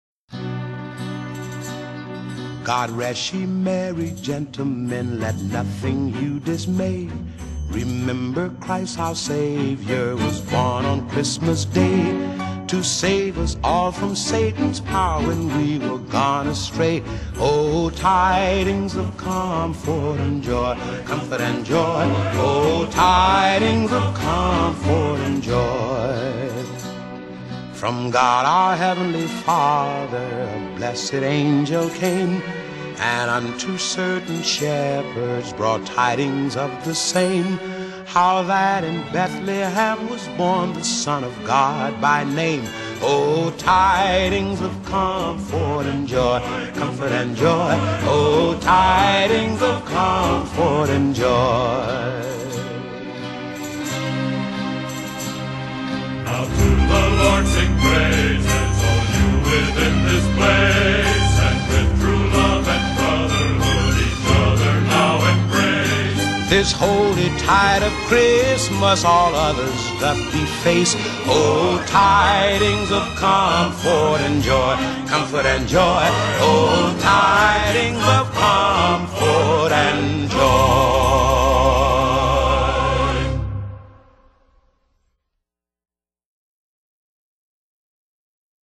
Genre: Vocal Jazz